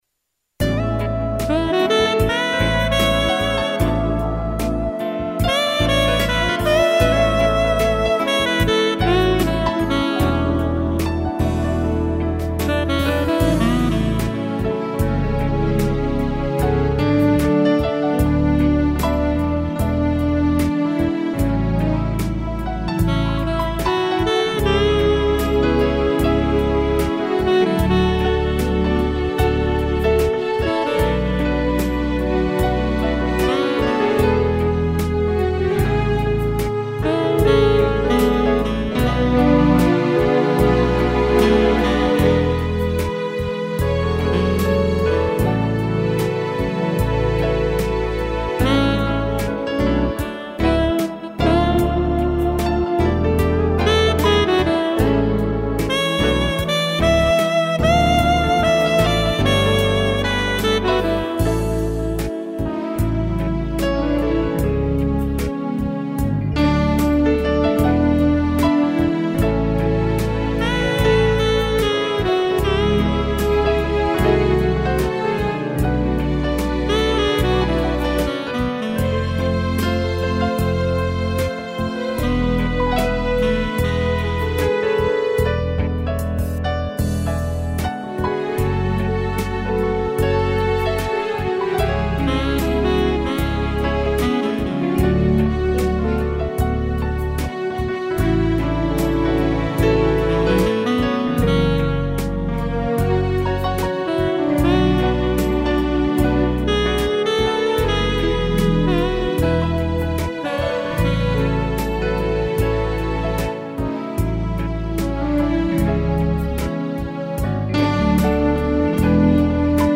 piano e sax e cello
(instrumental)